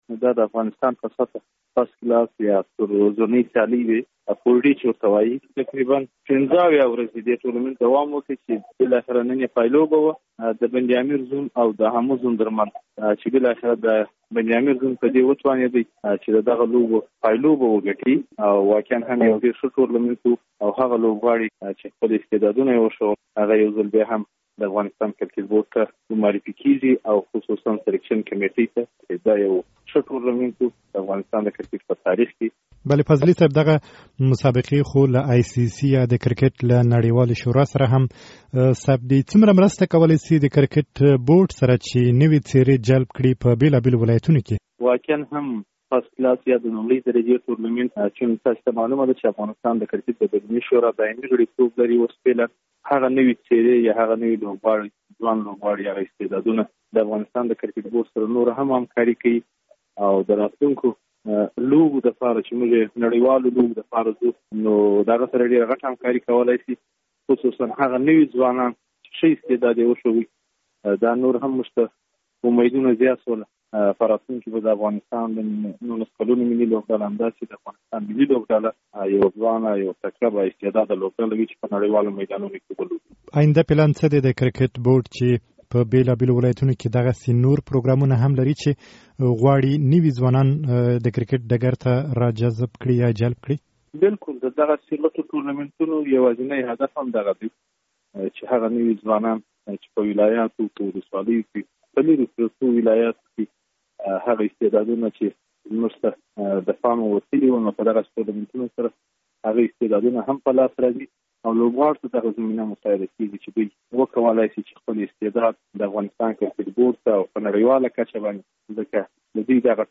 ورزشي مرکې